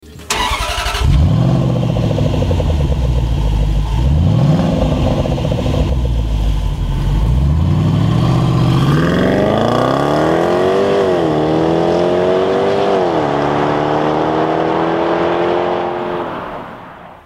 ENGINE SIZE 5.0 L V8